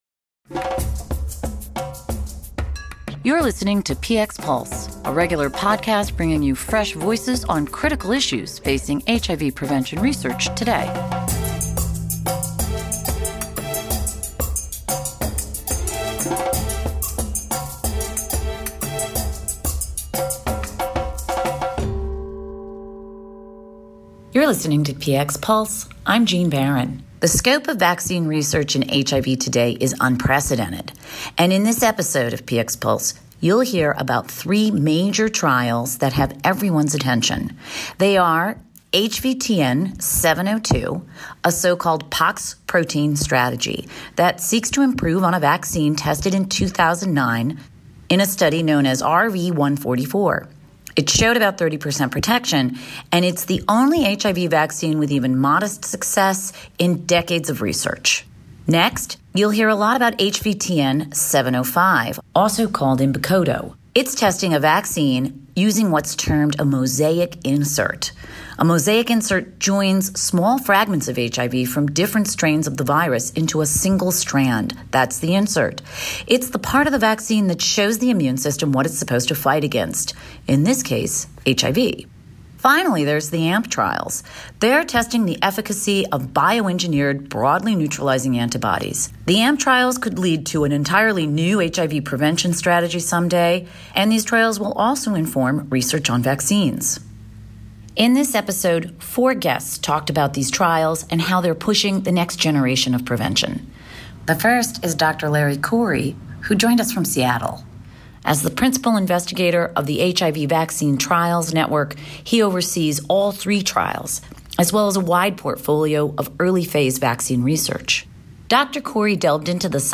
AVAC’s May episode of Px Pulse features four experts steeped in HIV vaccine research. Together they help set expectations for where the field is now and where it is going.